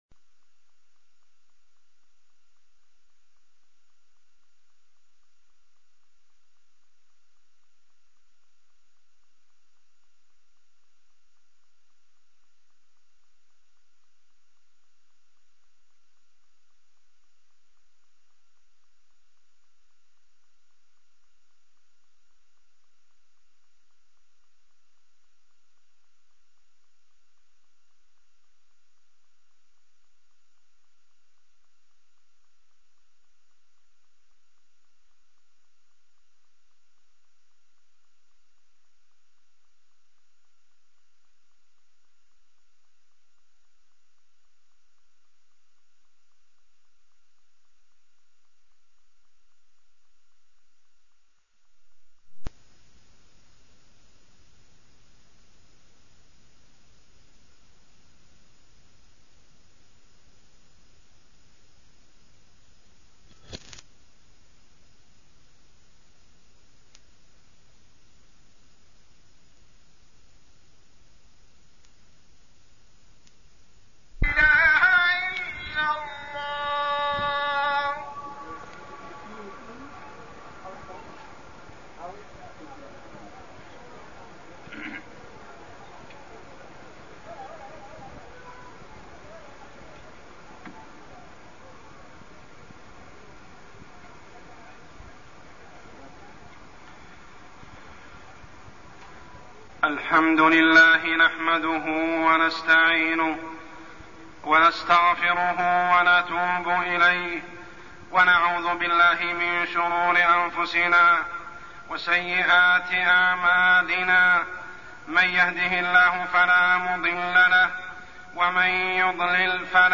تاريخ النشر ٩ رجب ١٤١٦ هـ المكان: المسجد الحرام الشيخ: عمر السبيل عمر السبيل حقوق ذوي القربى